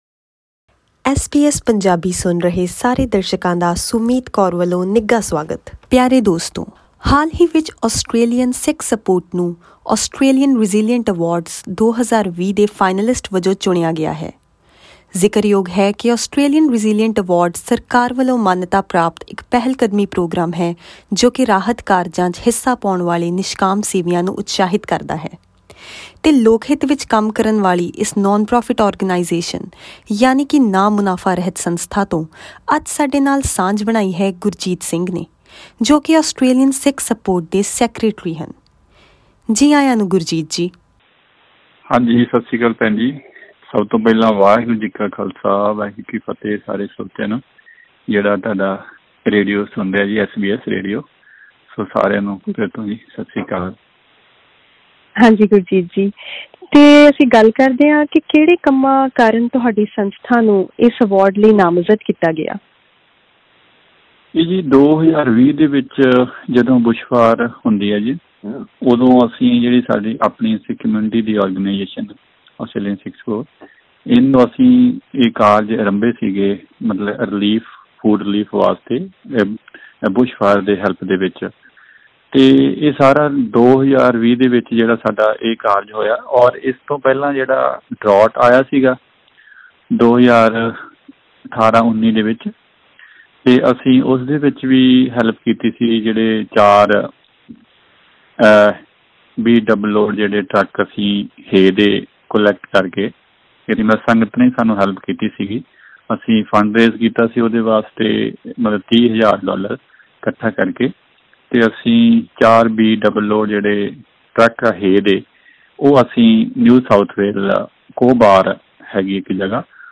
ਬੁਸ਼ਫਾਇਰ, ਸੋਕੇ ਅਤੇ ਕਰੋਨਾ ਮਹਾਂਮਾਰੀ ਦੌਰਾਨ ਨਿਸ਼ਕਾਮ ਸੇਵਾ ਲਈ 'ਆਸਟ੍ਰੇਲੀਅਨ ਸਿੱਖ ਸਪੋਰਟ' ਸੰਸਥਾ ਨੂੰ 'ਰੇਜ਼ੀਲੀਐਂਟ ਆਸਟ੍ਰੇਲੀਆ ਐਵਾਰਡ' ਦੇ ਫਾਈਨਾਲਿਸਟ ਵਜੋਂ ਚੁਣਿਆ ਗਿਆ ਹੈ। ਪੂਰੀ ਜਾਣਕਾਰੀ ਲਈ ਸੁਣੋ ਇਹ ਖਾਸ ਆਡੀਓ ਰਿਪੋਰਟ।